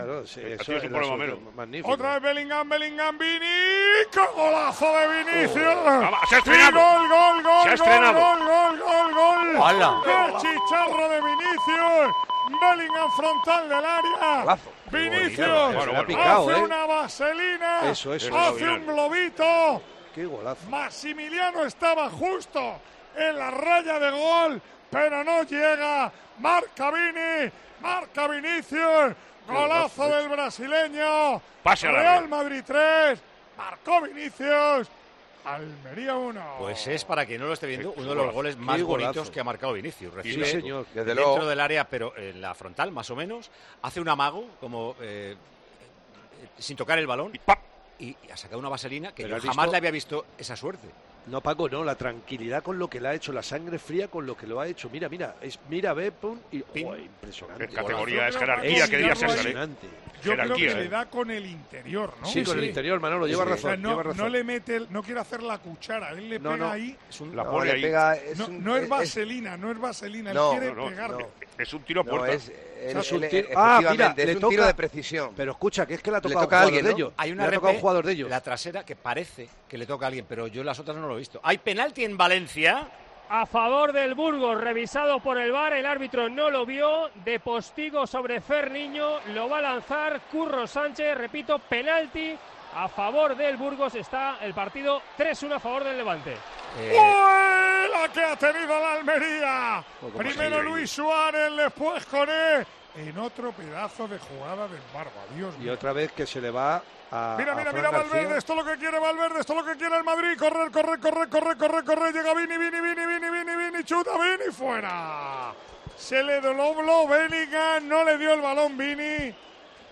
Así vivimos en Tiempo de Juego la retransmisión del Almería - Real Madrid